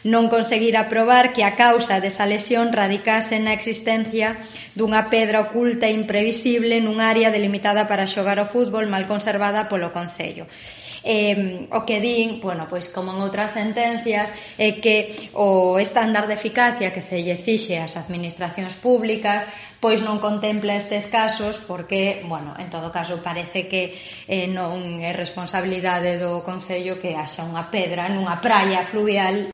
La portavoz del gobierno local de Pontevedra, Anabel Gulías, informa de la demanda desestimada